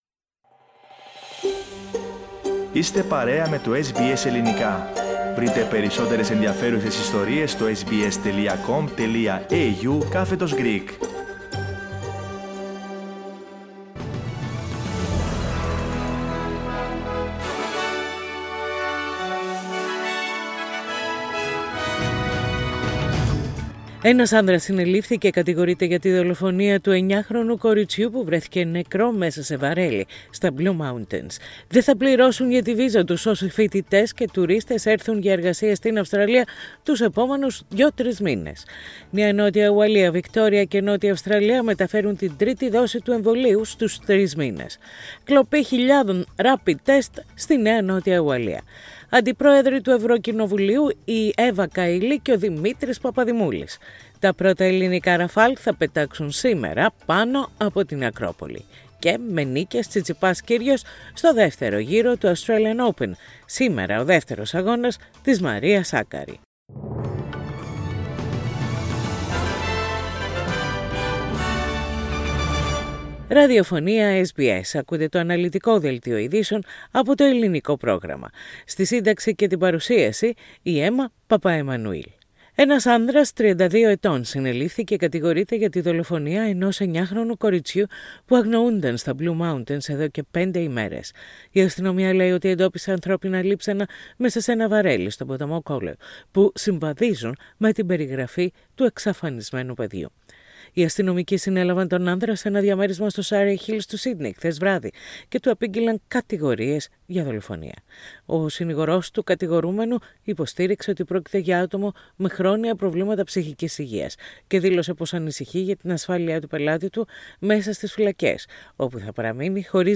Main bulletin of the day with news from Australia, Greece, Cyprus and the rest of the world.